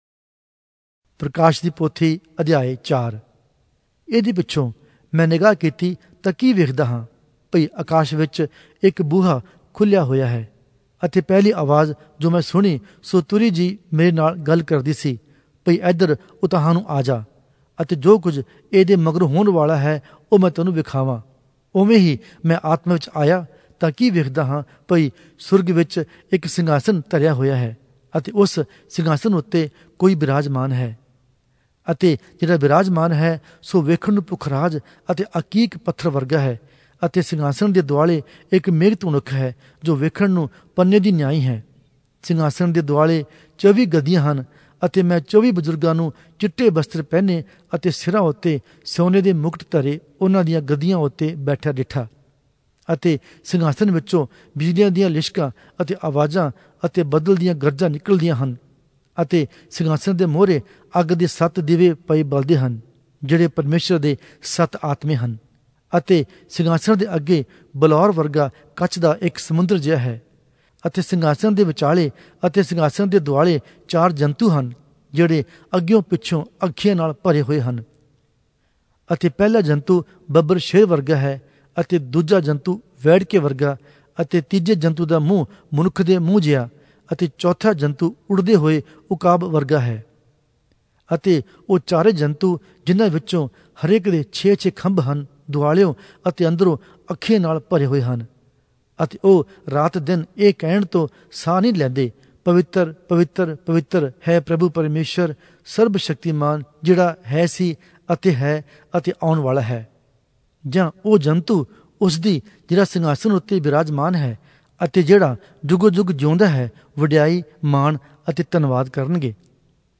Oriya Audio Bible - Revelation 17 in Pav bible version